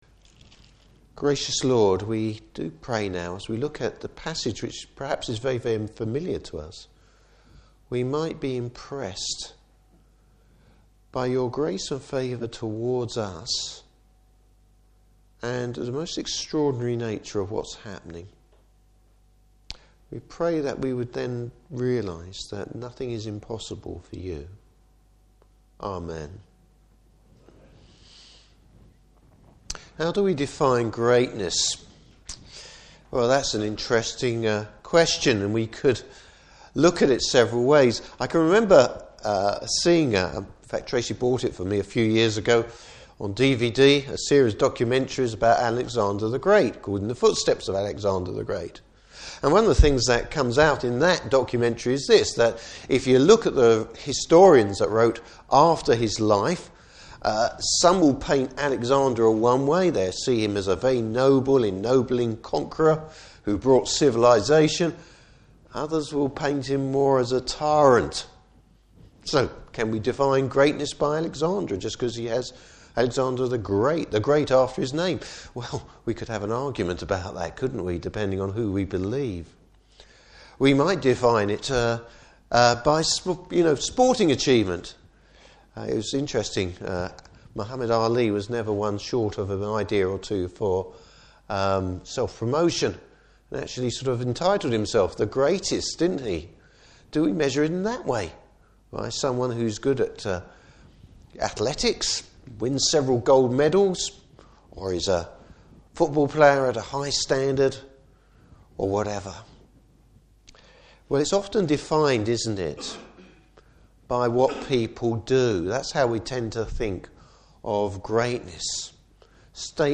Service Type: Morning Service Bible Text: Luke 1:26-38.